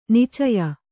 ♪ nicaya